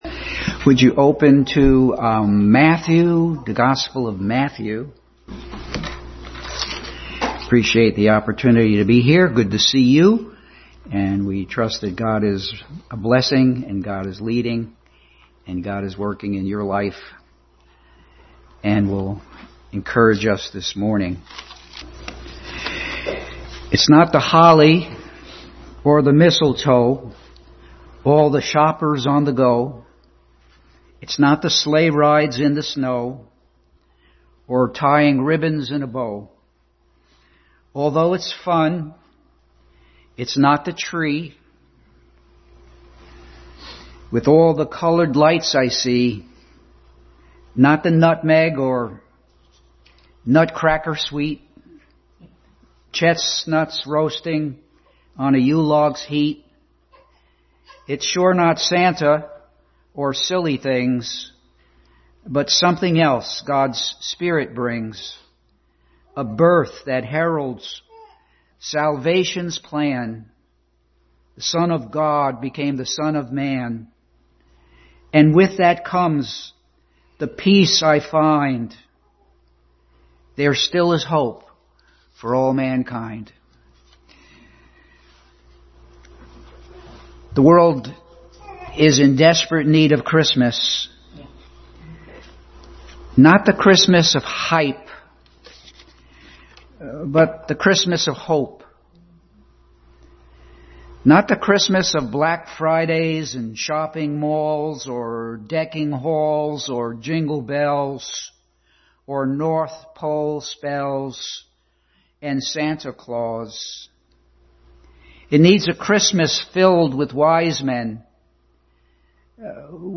The Greatest Gift Passage: Matthew 7:7-11 Service Type: Sunday School